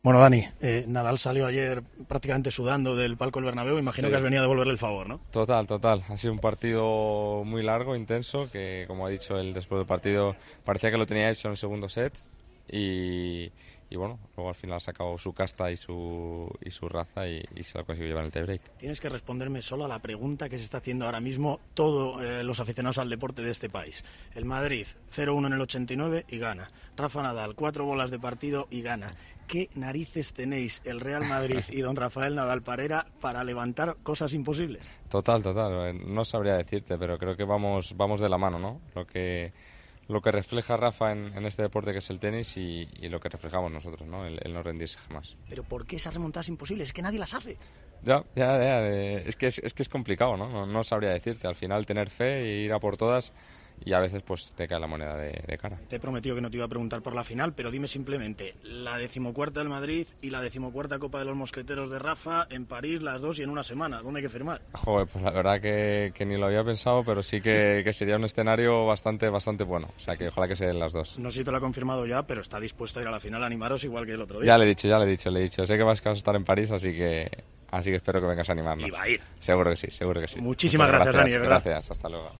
El futbolista del Real Madrid ha atendido a El Partidazo de COPE desde la Caja Mágica mientras veía el partido de Rafa Nadal.
Desde la grada, el futbolista del Real Madrid tuvo unos minutos para atender a El Partidazo de COPE.